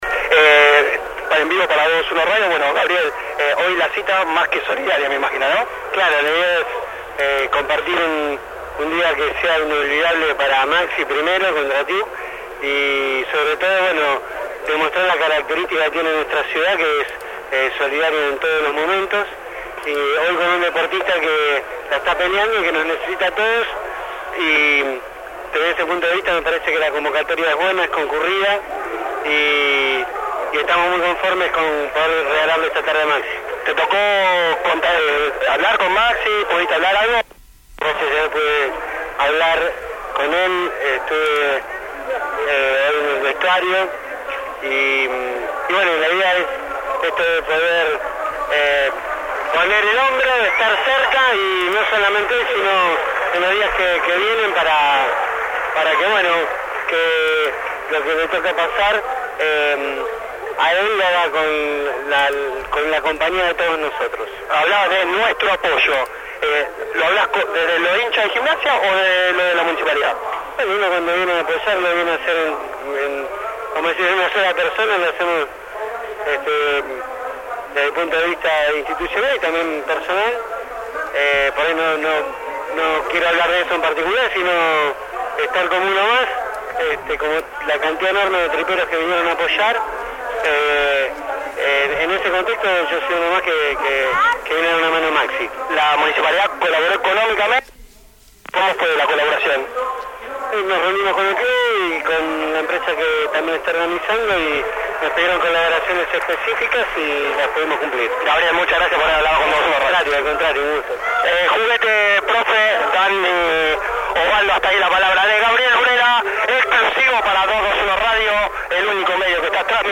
El concejal Gabriel Bruera en 221 Radio.